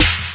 Snare (Good Life).wav